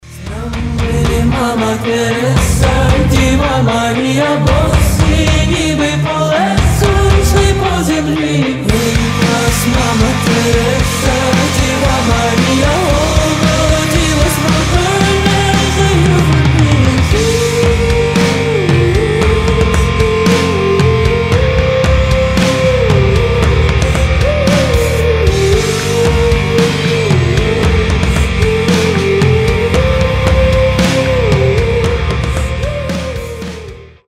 • Качество: 320, Stereo
Alternative Rock
рок-обработка
Rock cover